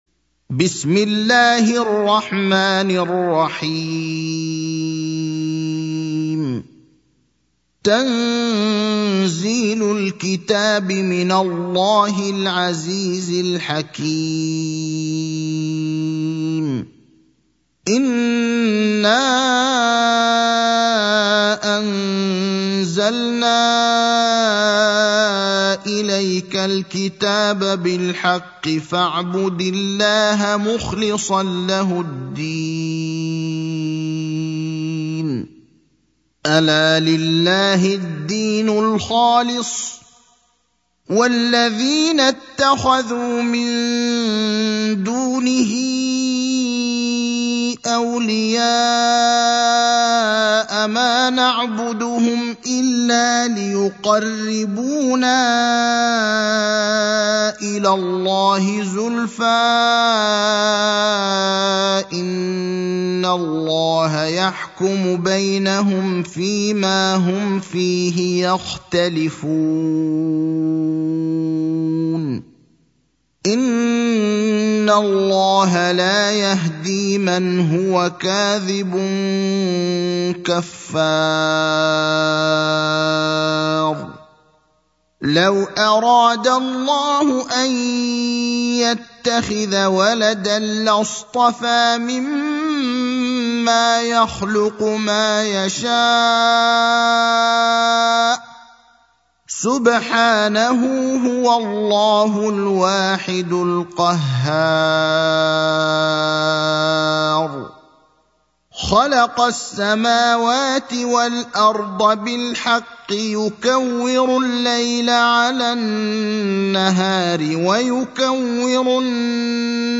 المكان: المسجد النبوي الشيخ: فضيلة الشيخ إبراهيم الأخضر فضيلة الشيخ إبراهيم الأخضر الزمر (39)